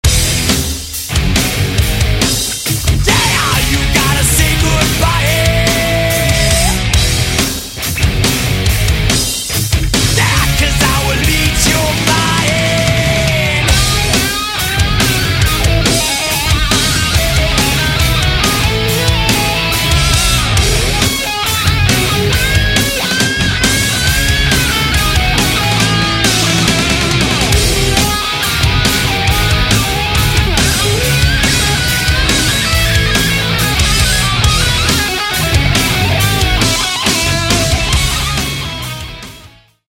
электрогитара
heavy Metal